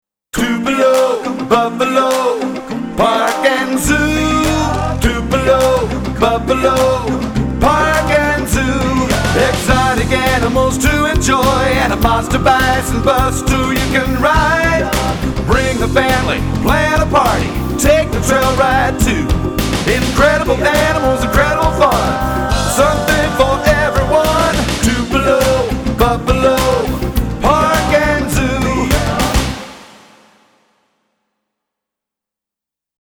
National quality jingles at competitive prices!